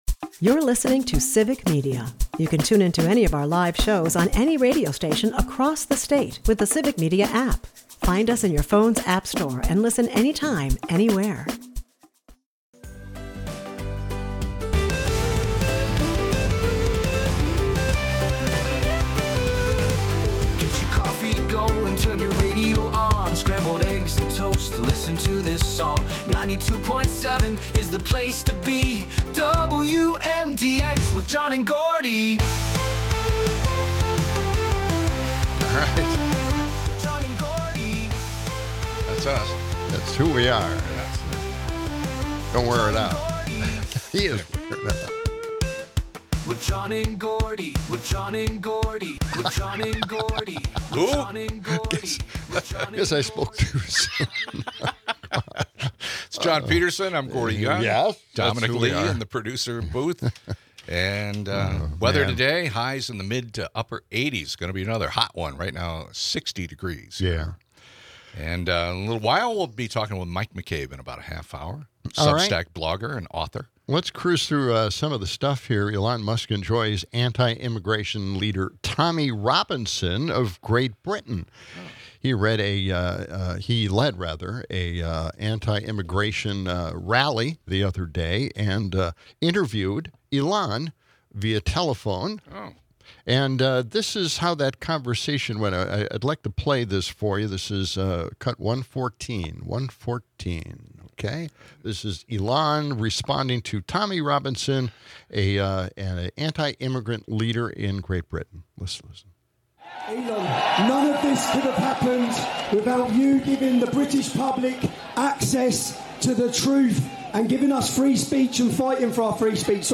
With a splash of weather updates and listener calls, the show underscores a nation at a crossroads.